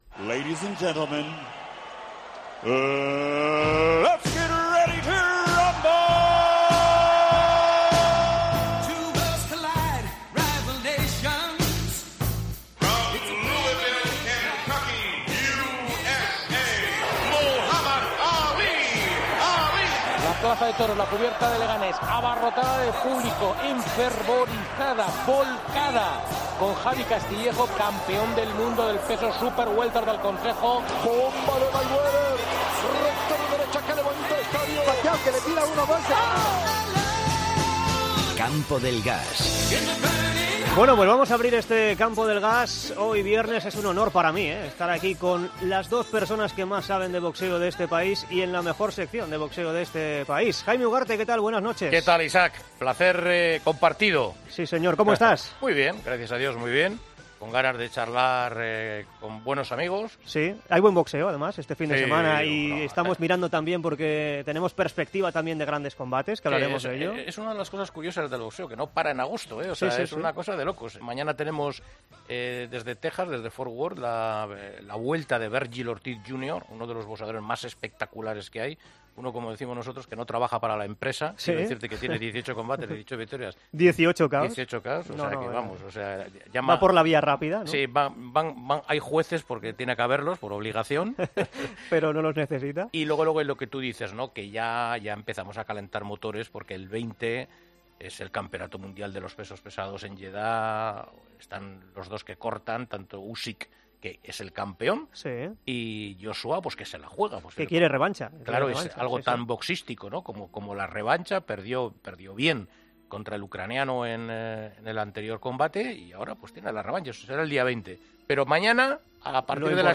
Entrevista a Kiko Martínez. Charlamos de la velada del 20 de agosto entre Anthony Joshua y Alexander Usyk.